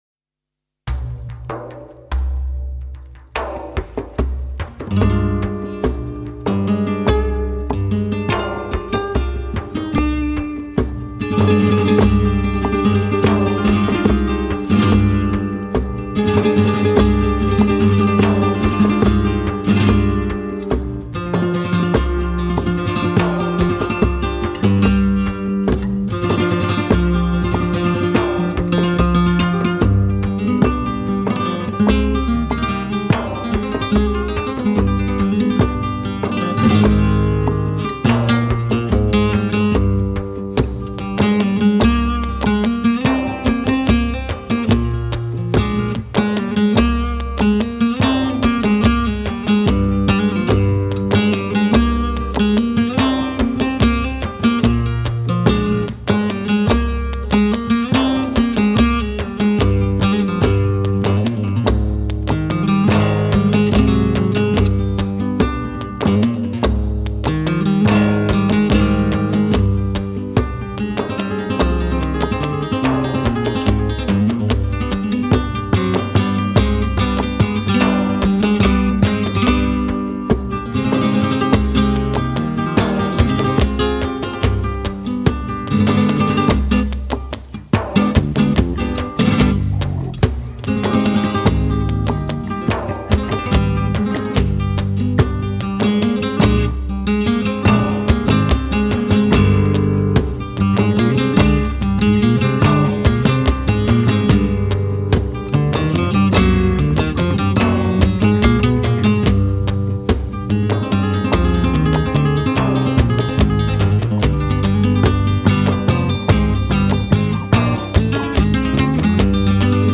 Templo-de-Diana-Soleá-por-Bulería.mp3